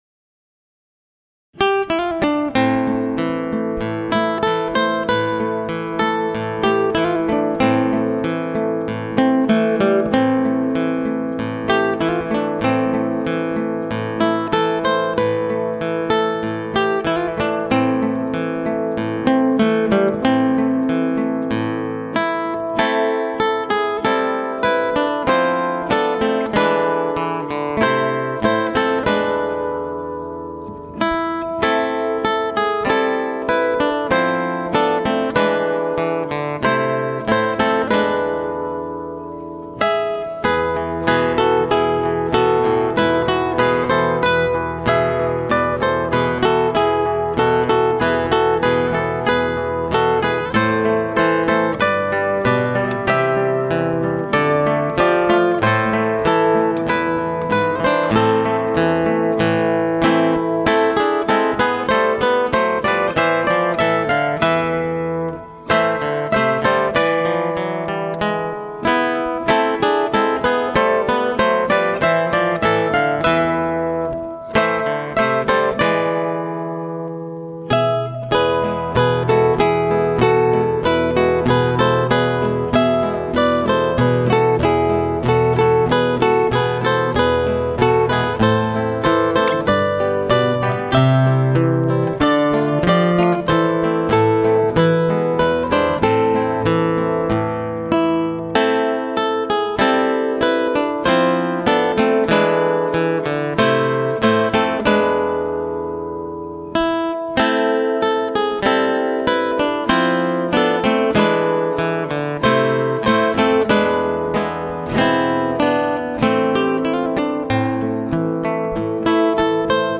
4 Gitarren